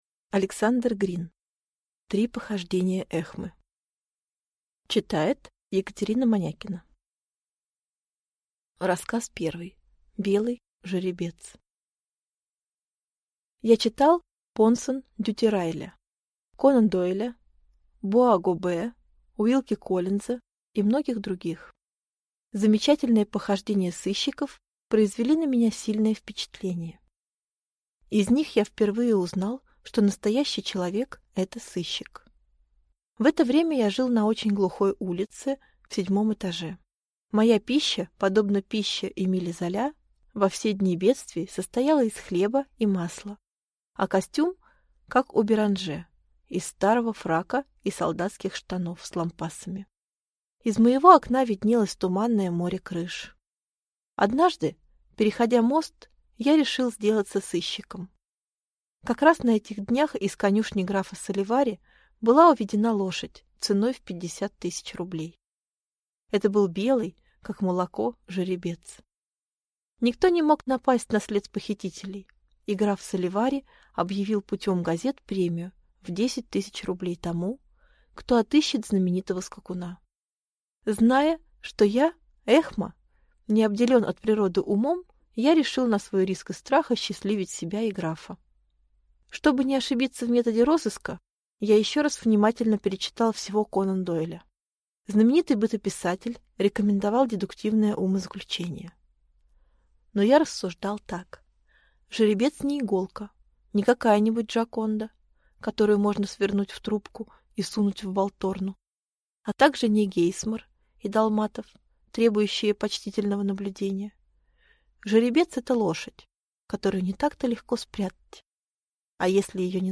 Аудиокнига Три похождения Эхмы | Библиотека аудиокниг